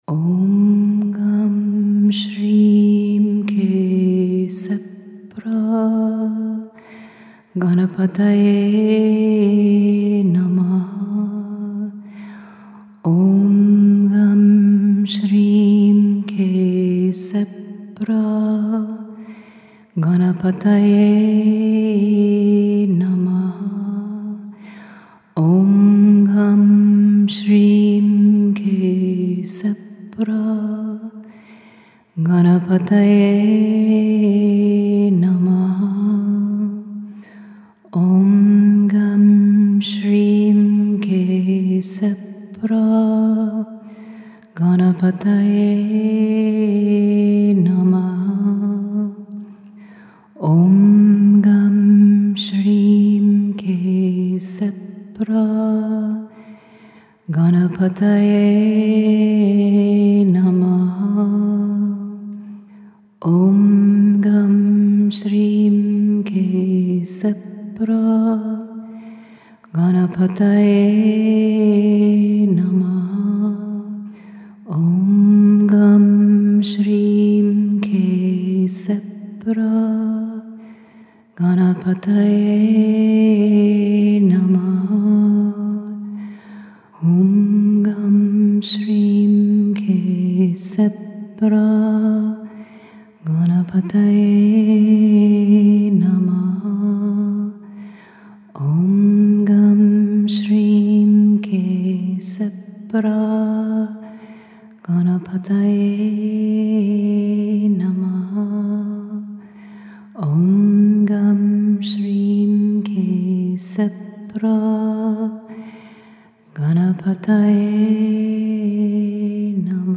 The Mantra